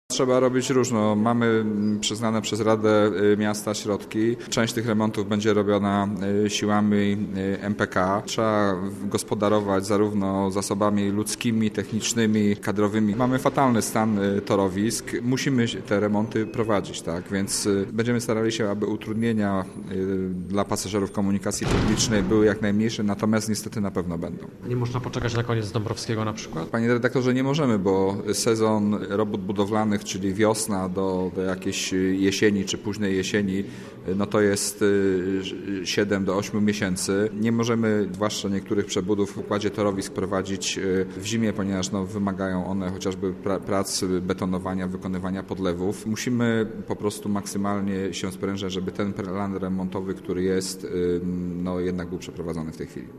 Naszym gościem był Miejski Inżynier Ruchu, Łukasz Dondajewski, który zatwierdza każdy remont w Poznaniu i odpowiada za organizację ruchu w mieście.